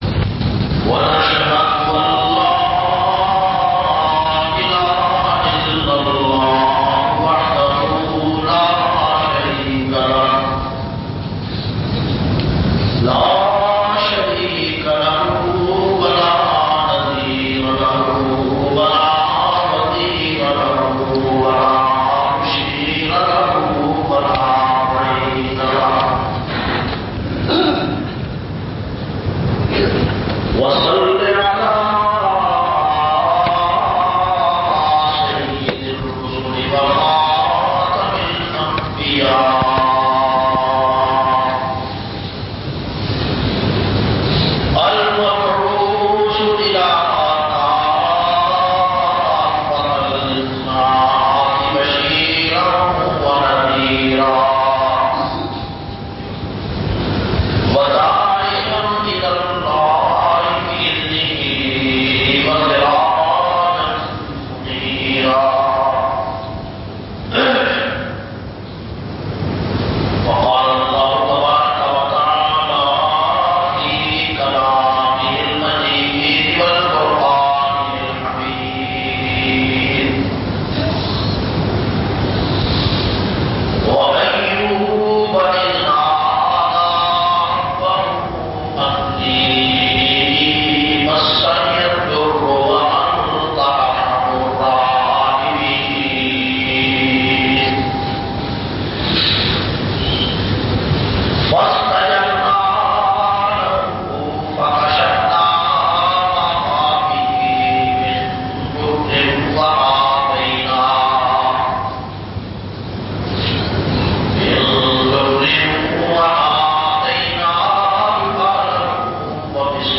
475- Hazrat Ayub A.S Jumma khutba Jamia Masjid Muhammadia Samandri Faisalabad.mp3